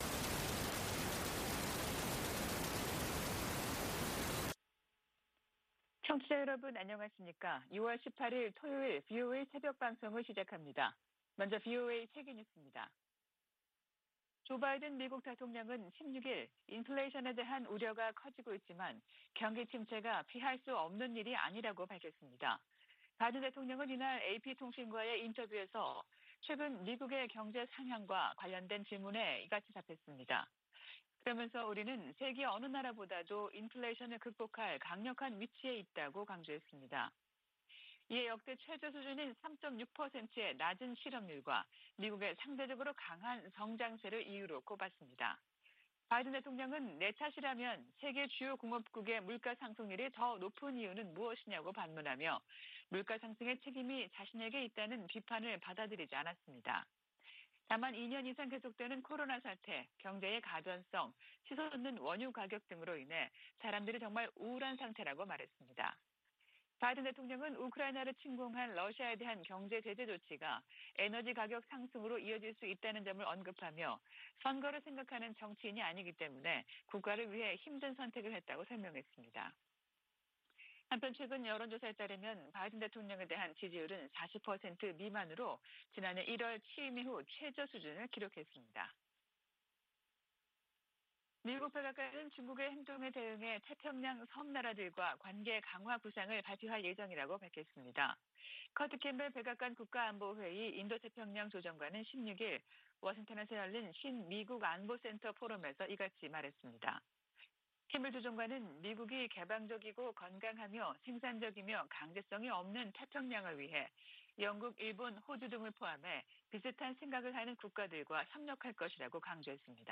VOA 한국어 '출발 뉴스 쇼', 2022년 6월 18일 방송입니다. 미 국무부가 대화와 외교로 북한 핵 문제를 해결한다는 바이든 정부 원칙을 거듭 밝혔습니다. 북한의 7차 핵실험 가능성이 계속 제기되는 가운데 미국과 중국이 이 문제를 논의하고 있다고 백악관 고위 당국자가 밝혔습니다.